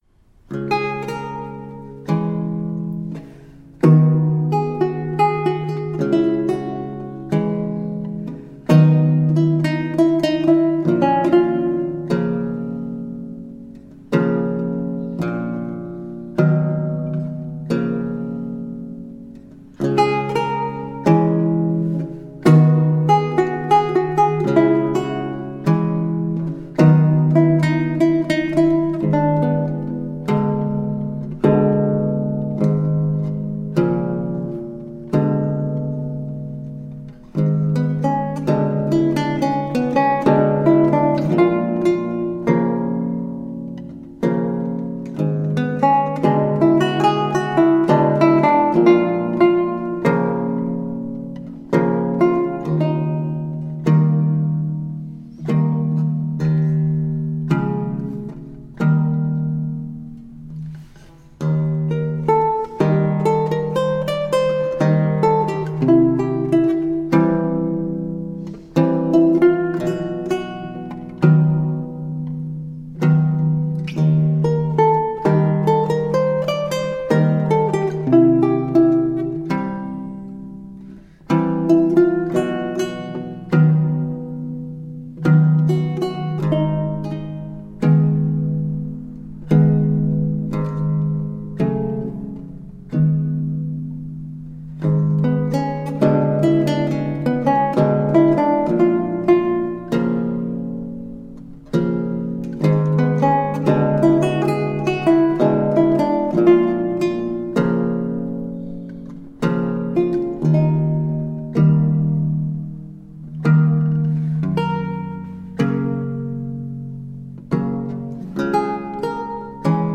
Classical Guitar, Lute